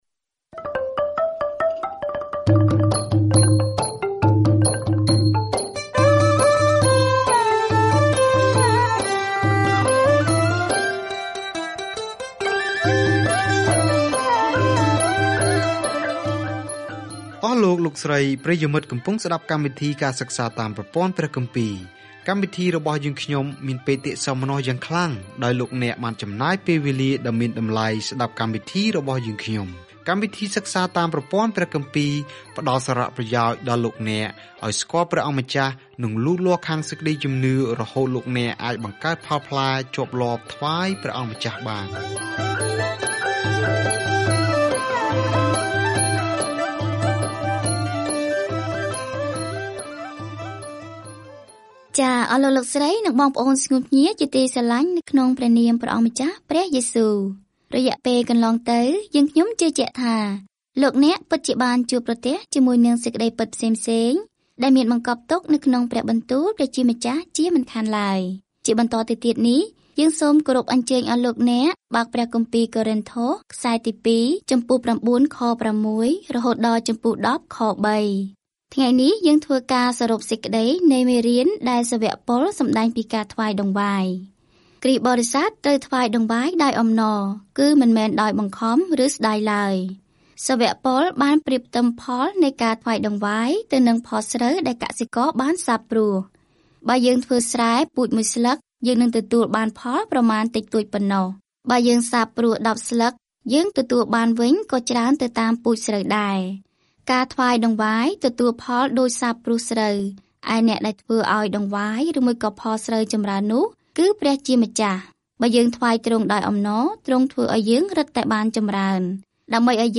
ការធ្វើដំណើរប្រចាំថ្ងៃតាមរយៈ កូរិនថូសទី 2 នៅពេលអ្នកស្តាប់ការសិក្សាជាសំឡេង ហើយអានខគម្ពីរដែលជ្រើសរើសចេញពីព្រះបន្ទូលរបស់ព្រះ។